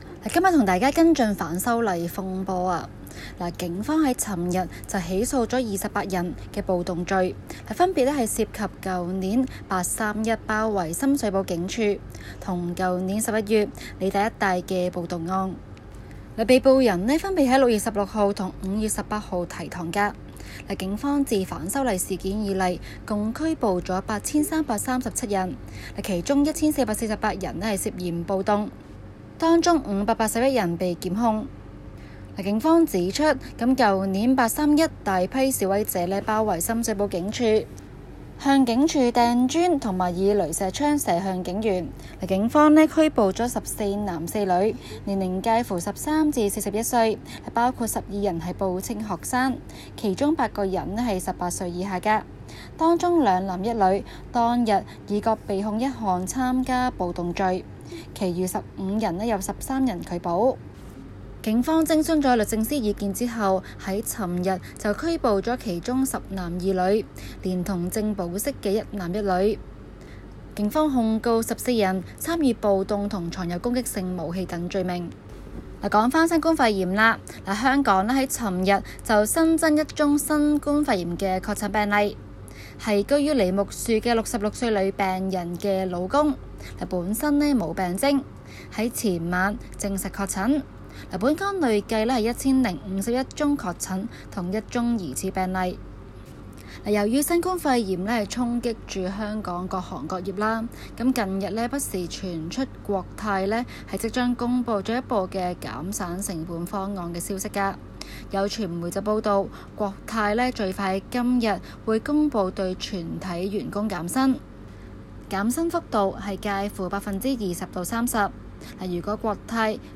今期 【中港快訊 】環節報道香港今期DSE 文憑試歷史科試題惹來非議。